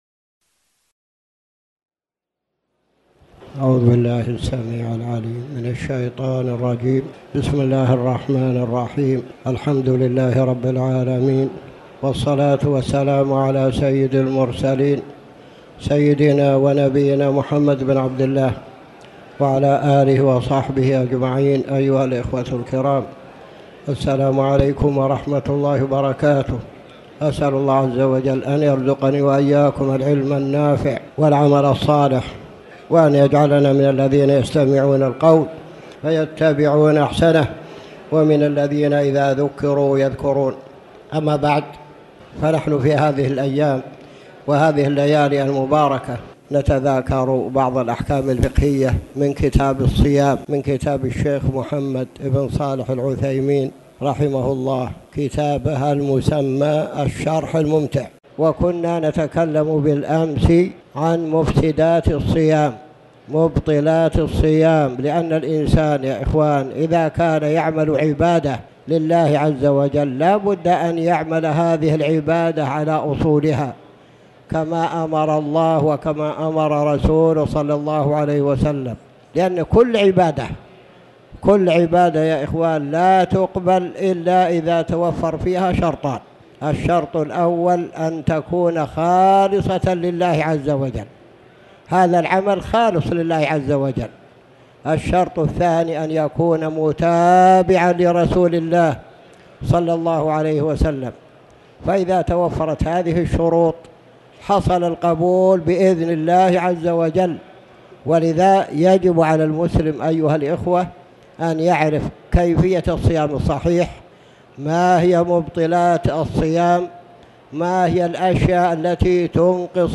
تاريخ النشر ٢٠ جمادى الأولى ١٤٣٩ هـ المكان: المسجد الحرام الشيخ